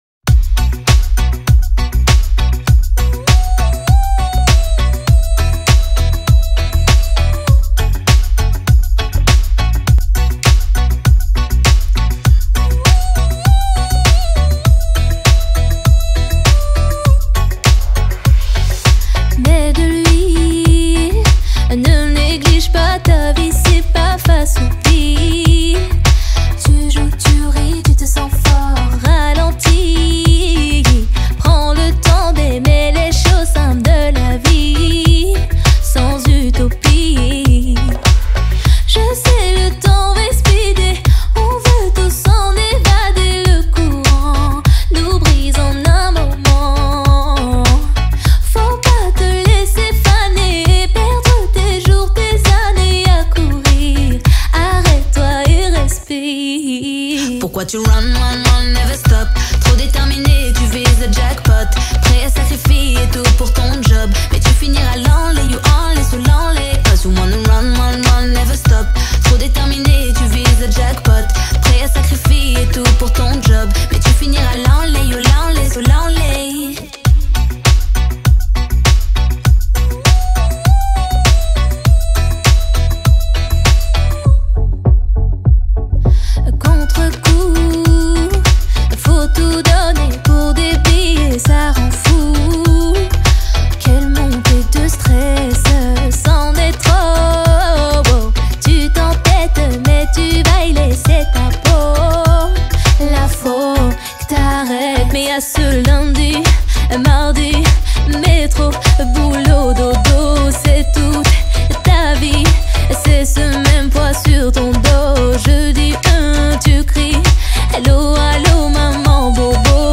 французской певицы
сочетает элементы поп и мирной музыки